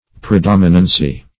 Predominancy \Pre*dom"i*nan*cy\, n.
predominancy.mp3